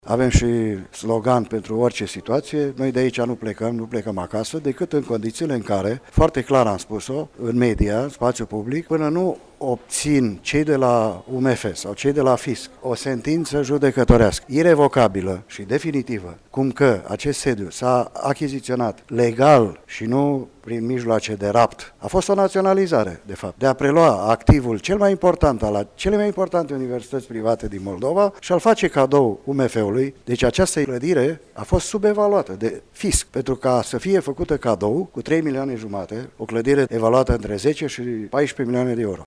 Şi la Universitatea „Petre Andrei” din Iaşi a avut loc astăzi deschidere noului an academic.